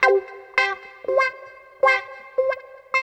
137 GTR 4 -R.wav